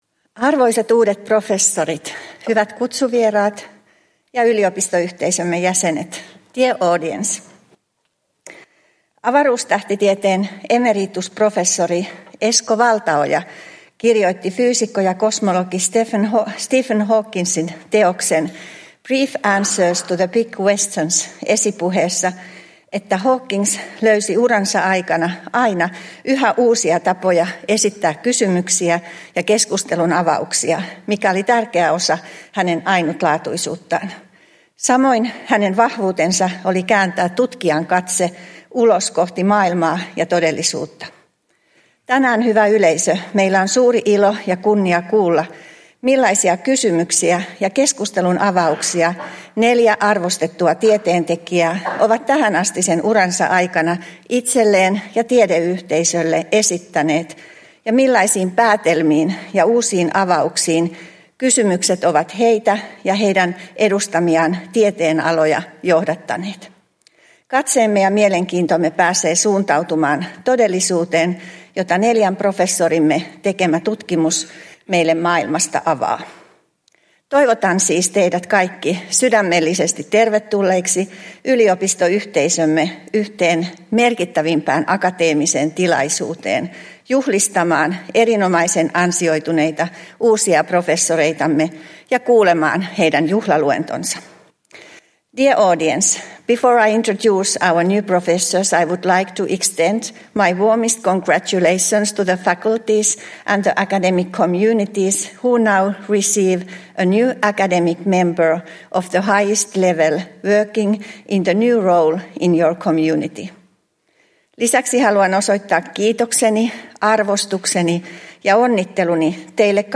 C1-salin tilaisuuden avaus
Uusien professoreiden juhlaluennot 2024